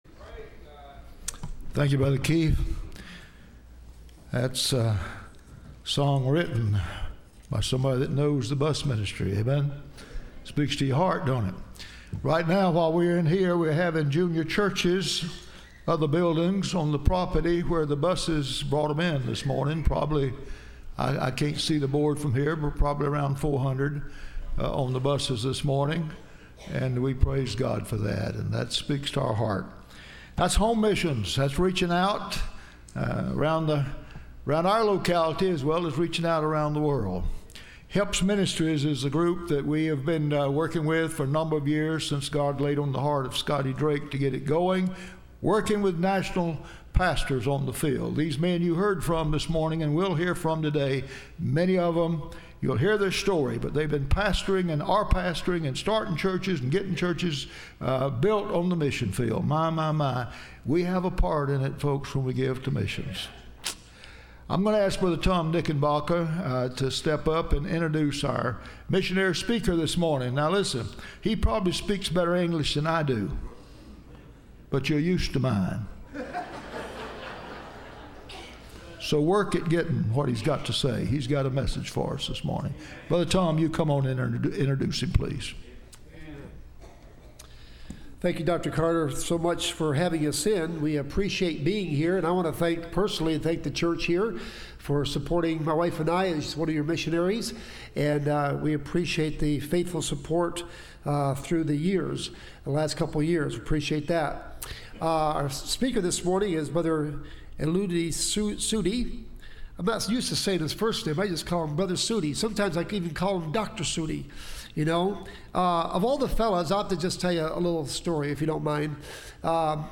Listen to Message
Service Type: Missions Conference